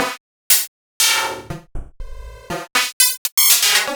FM Hitter 01.wav